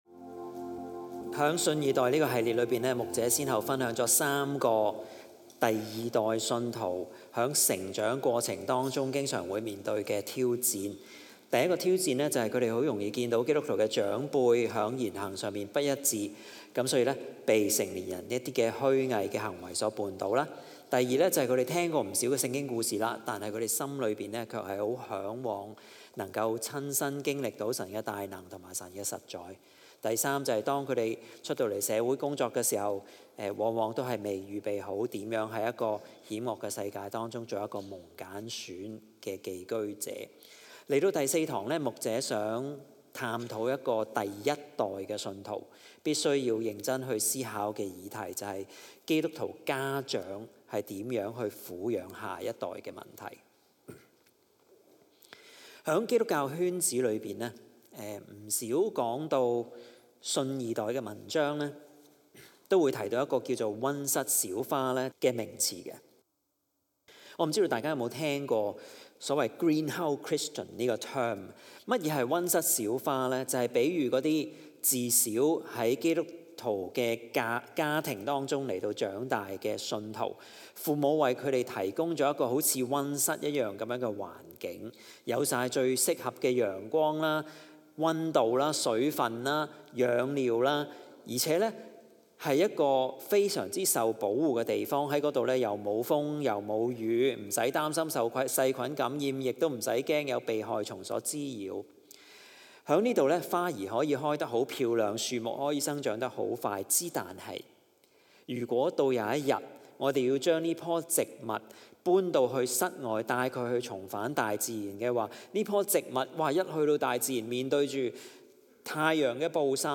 在這個「信二代」系列的第四堂，講員帶領我們探討基督徒家長撫養下一代的策略，反思我們是否正將孩子養育成「溫室小花」，並提出應如何轉向「預備孩子走最難的路」而非「預備最好的路給孩子」。